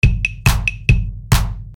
标签： 140 bpm House Loops Drum Loops 295.48 KB wav Key : Unknown
声道立体声